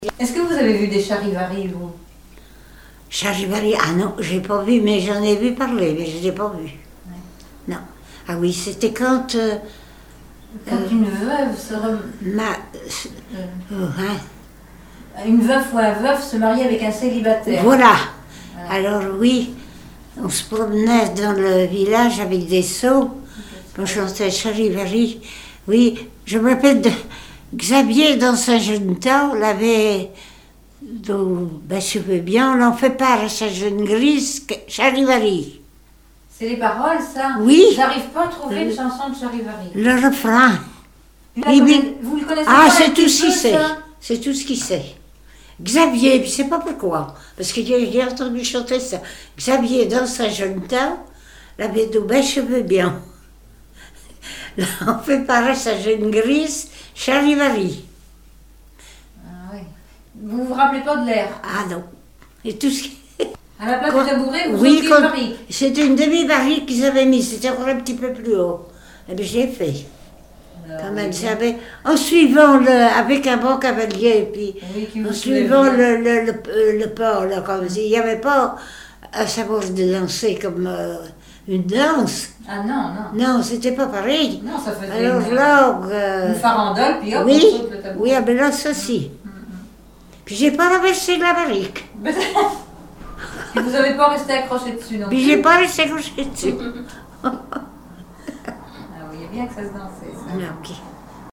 Enquête Arexcpo en Vendée-Association Joyeux Vendéens
Catégorie Témoignage